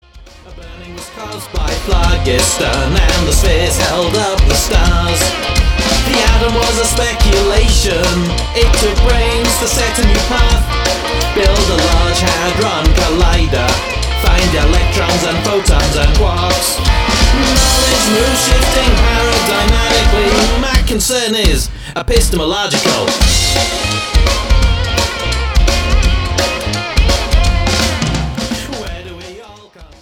Jewish rock, blues and soulful liturgical settings